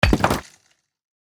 axe-mining-stone-2.ogg